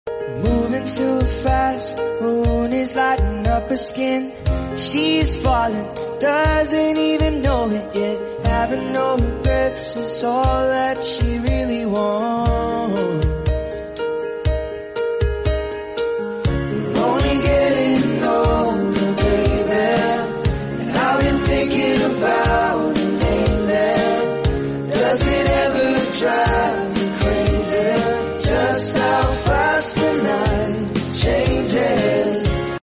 Relaxing 🐠🐟 sound effects free download